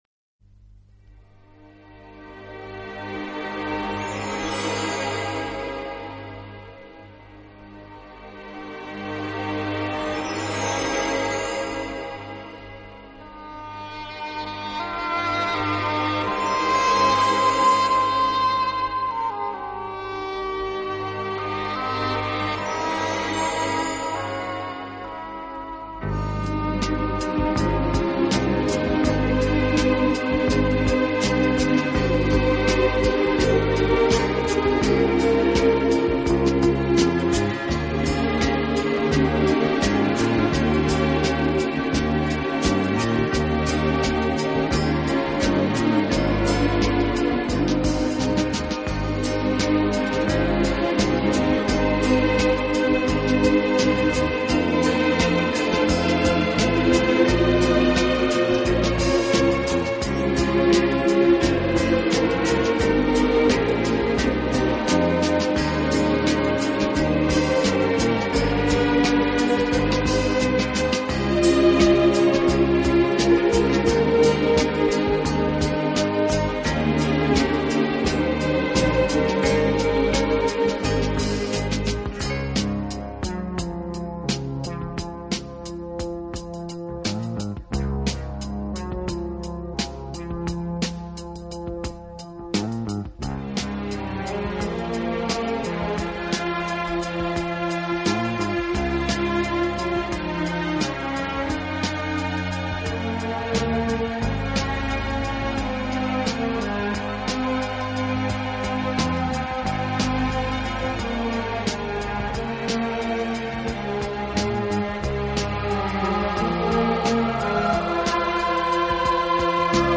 Genre: Instrumental / Classical